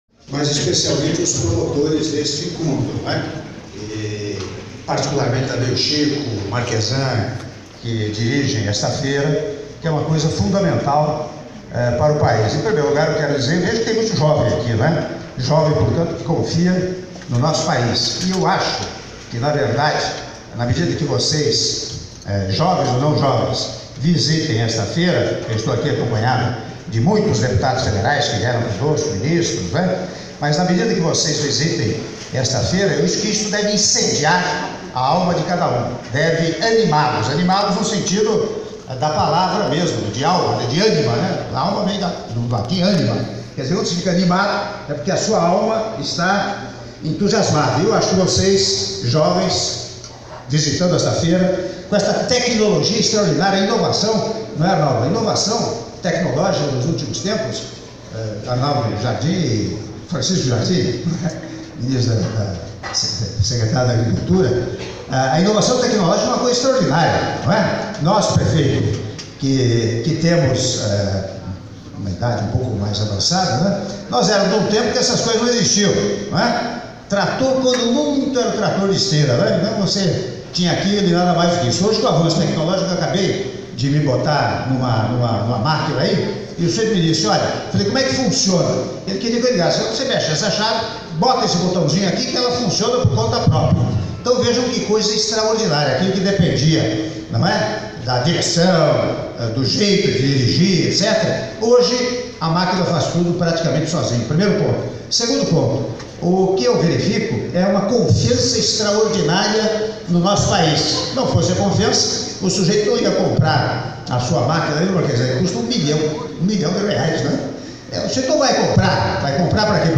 Áudio do Discurso do Presidente da República, Michel Temer, durante a visita à 25ª Edição da Feira Internacional de Tecnologia Agrícola em Ação (Agrishow) - (03min43s) — Biblioteca